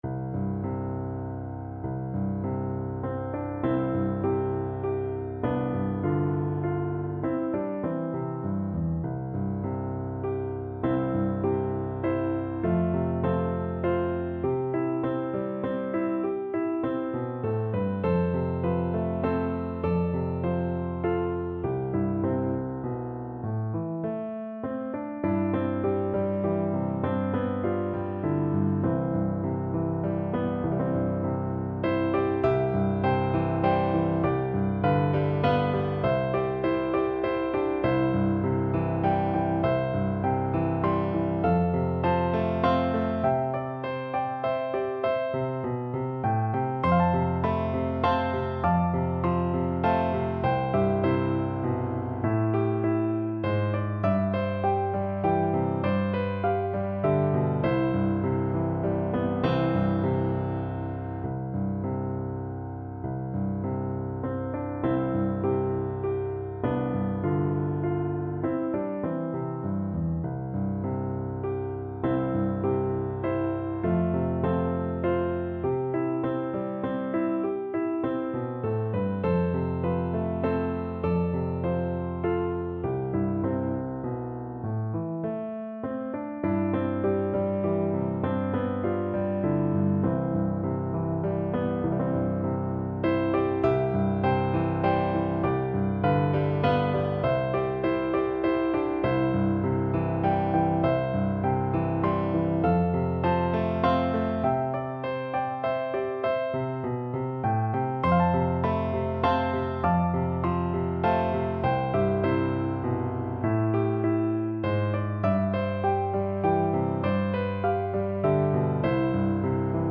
Piano version
No parts available for this pieces as it is for solo piano.
3/4 (View more 3/4 Music)
Longingly, nostalgically =c.100